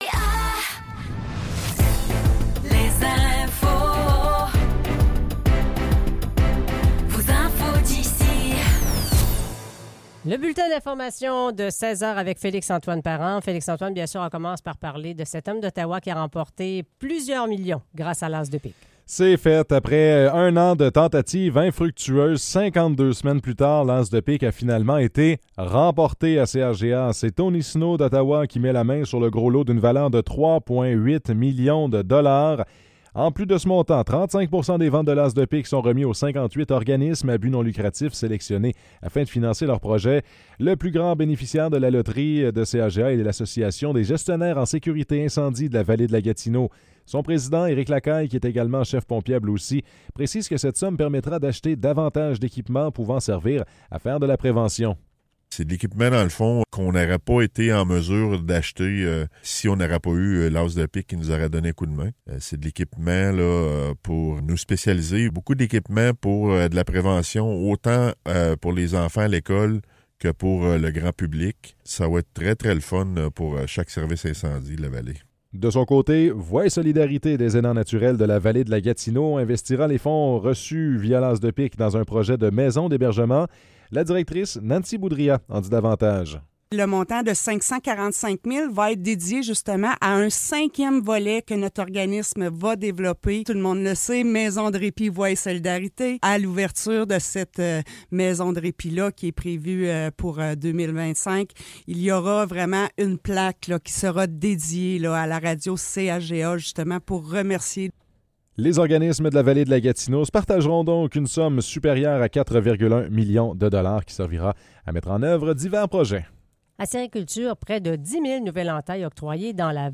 Nouvelles locales - 5 septembre 2024 - 16 h